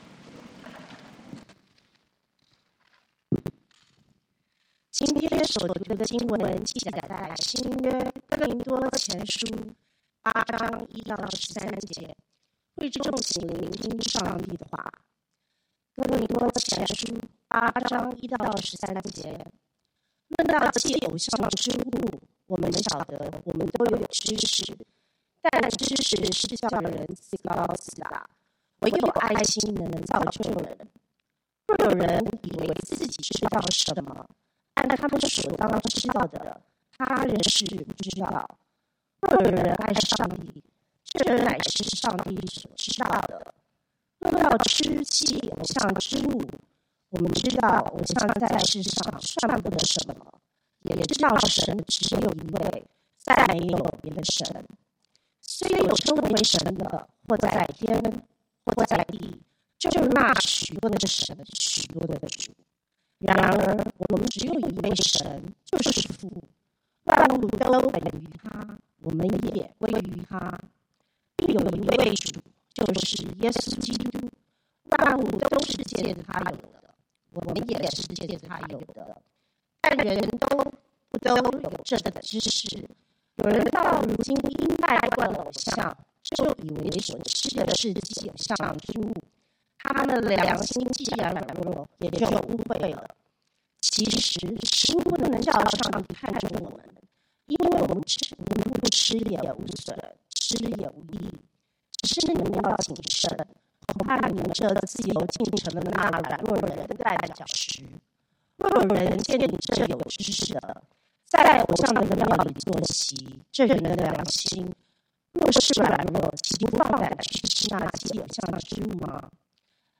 講道經文：哥林多前書 1 Corinthians 8:1-13